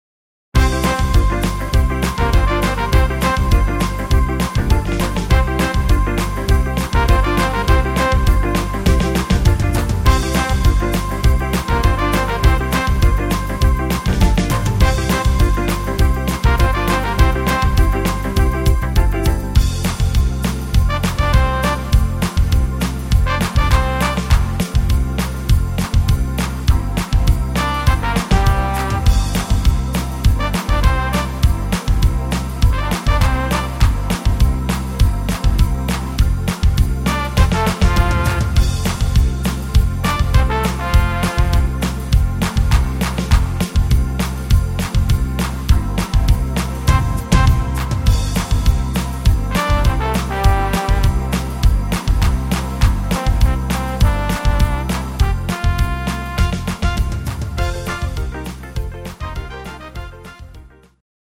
instr. Orchester